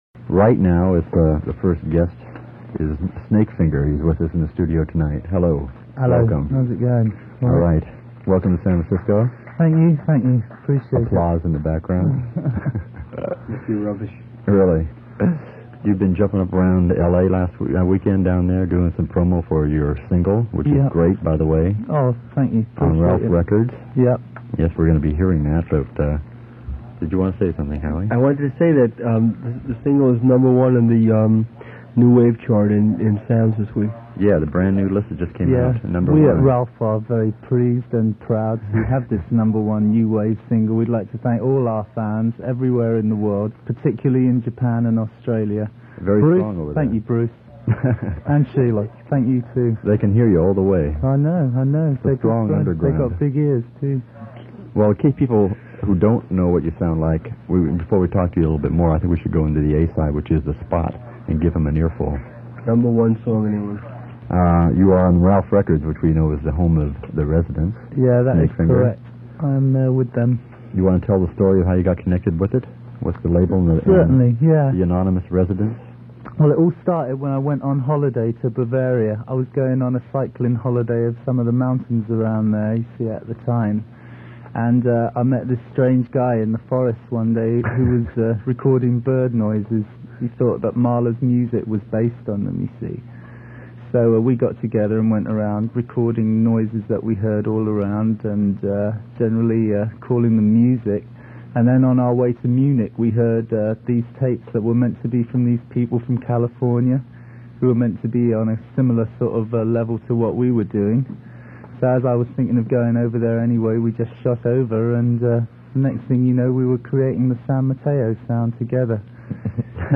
1978 interview with Snakefinger on KSAN FM.
Snakefinger_-_KSAN_FM_Interview_1978.mp3